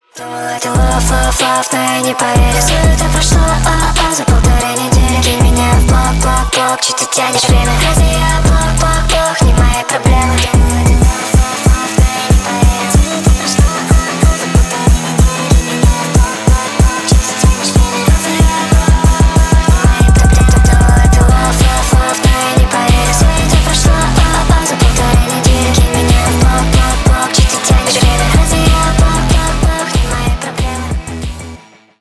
Поп Музыка
ритмичные